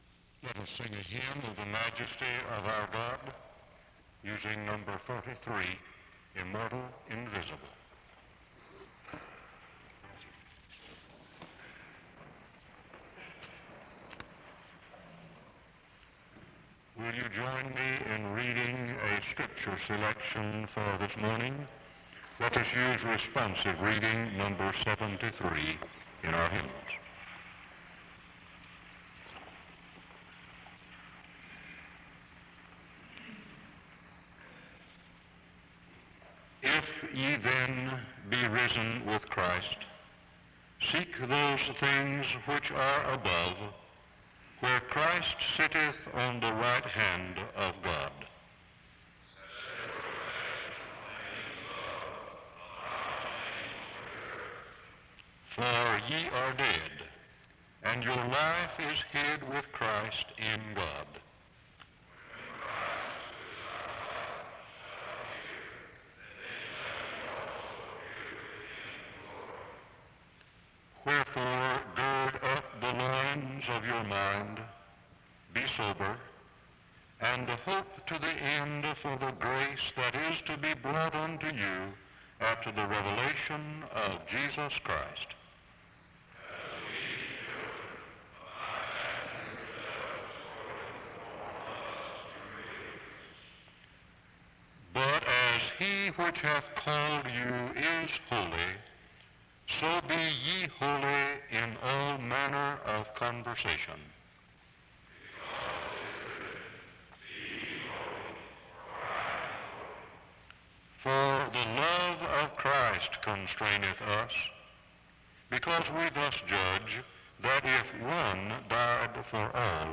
Download .mp3 Description The service opens with responsive reading #73 in the hymnals (00:00-02:50) and prayer (02:51-06:36).
He ends the service in prayer (17:32-18:21), and the audio ends with instrumental music (18:21-18:33).